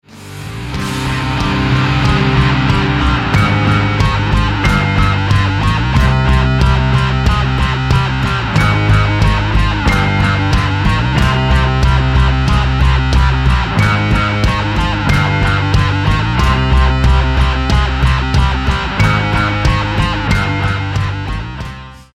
американская рок-группа